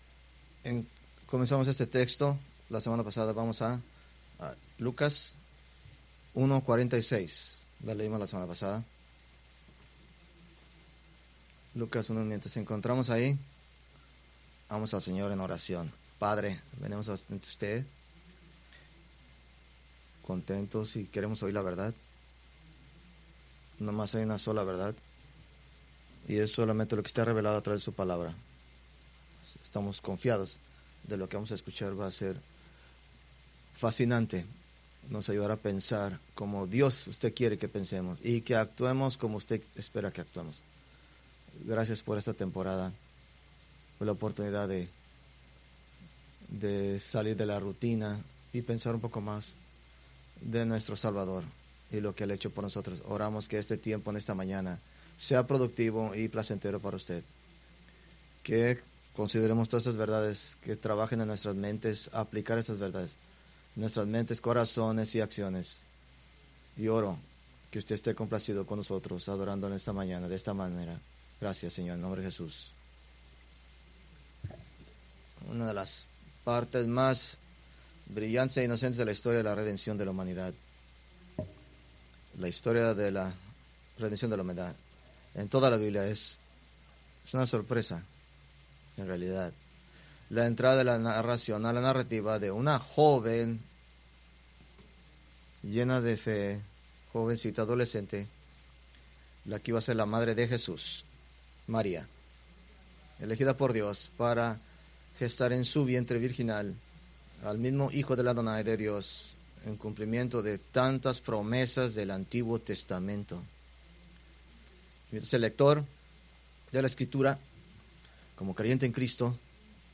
Preached December 7, 2025 from Lucas 1:48-49